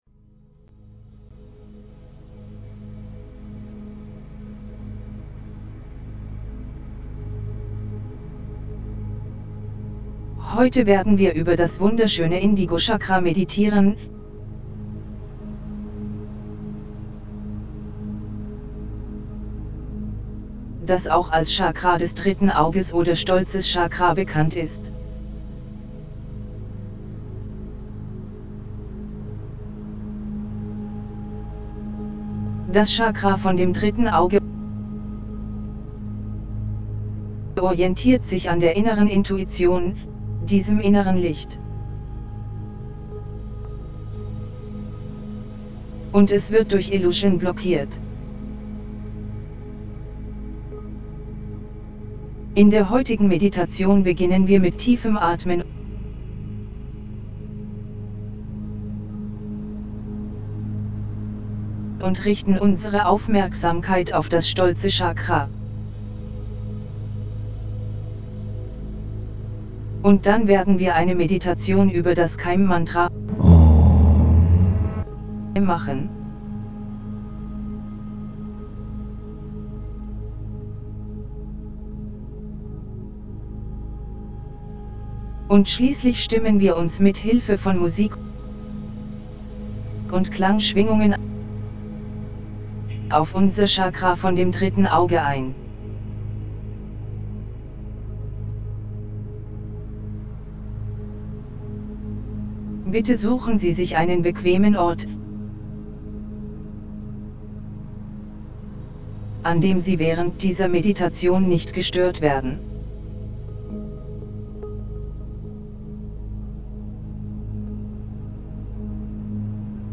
6ThirdEyeChakraHealingGuidedMeditationDE.mp3